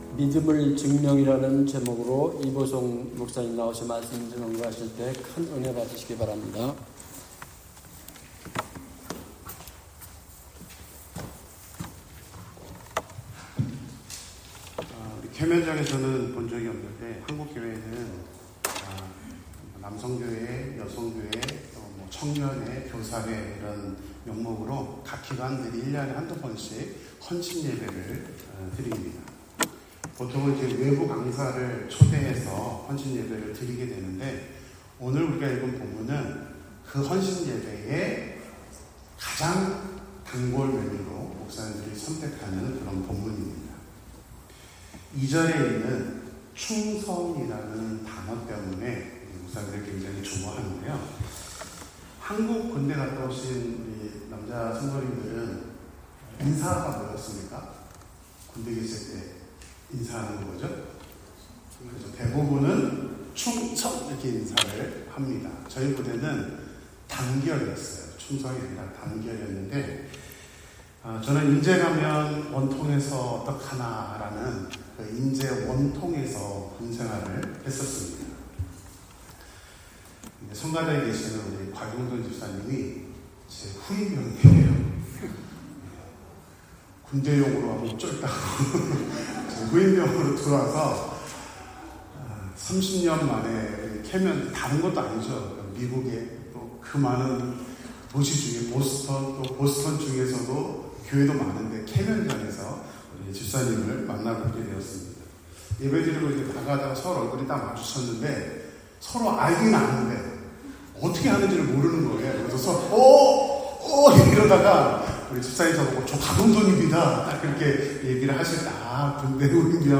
Bible Text: 고전 4:1-2 | 설교자